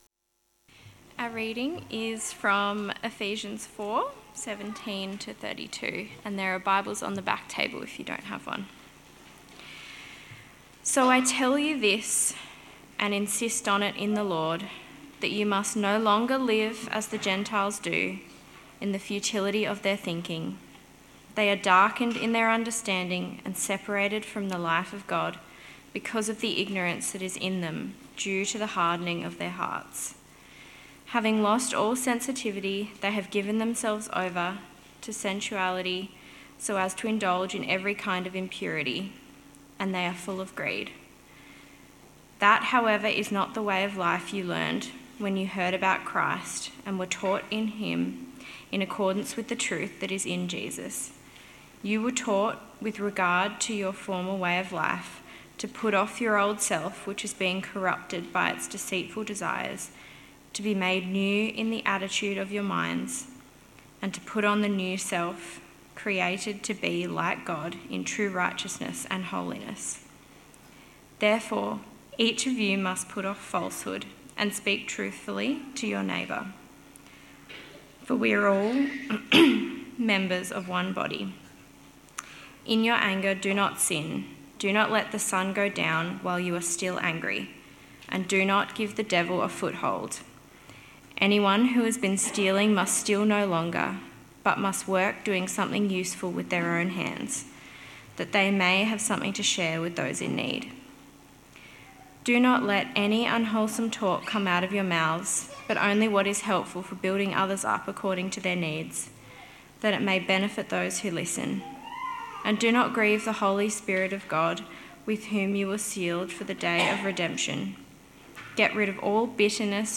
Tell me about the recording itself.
Sermon preached at The Branch Christian Church on August 2nd, 2024.